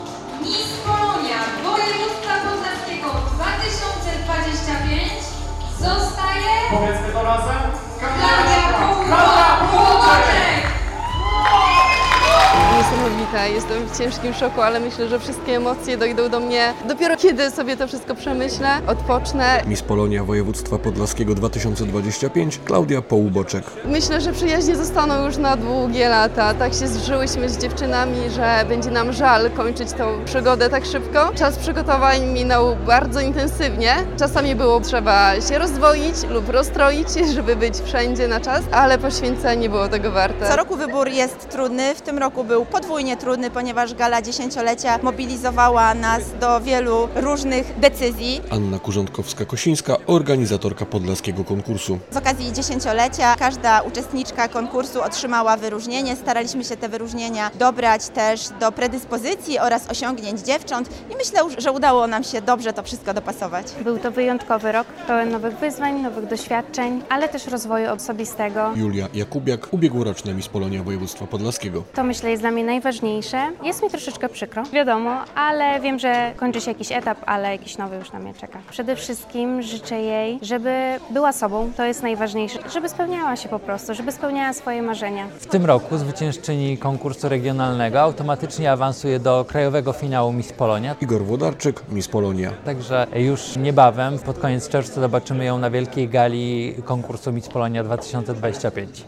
Gala finałowa Miss Polonia Województwa Podlaskiego